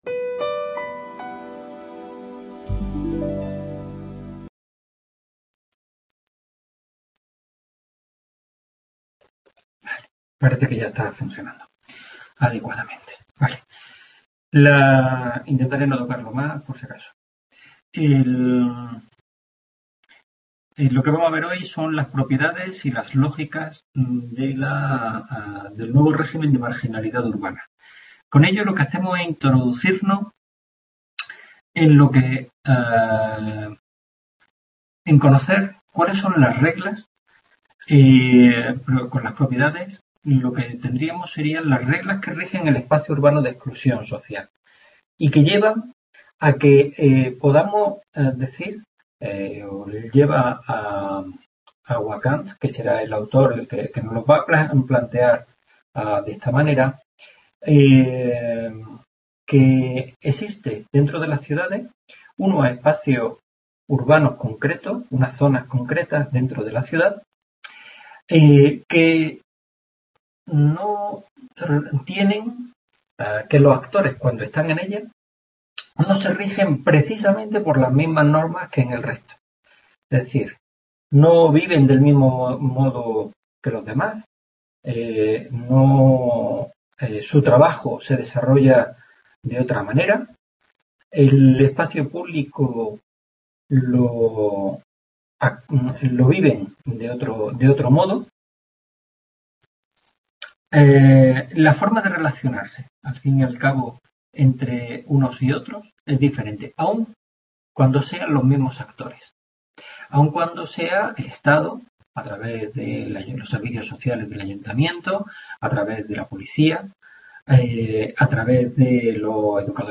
Tutoría de la asignatura Delincuencia y Vulnerabilidad